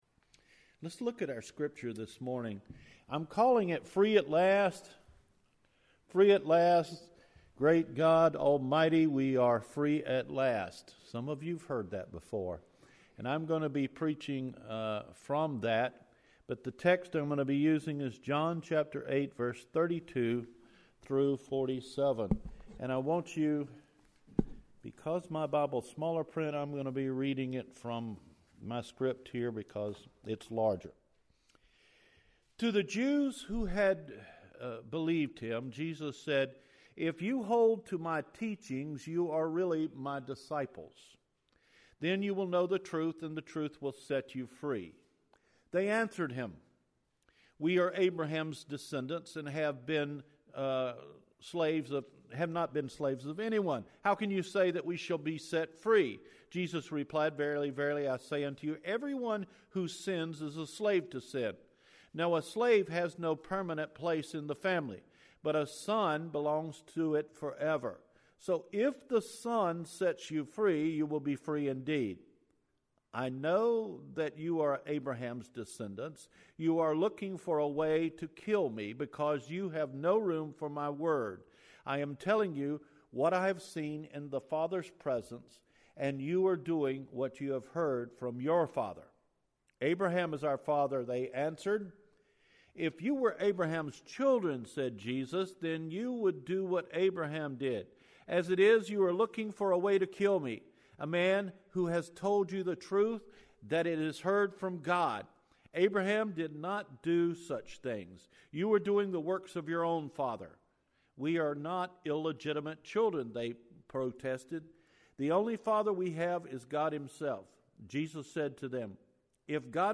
Free at Last – March 4 Recorded Sermon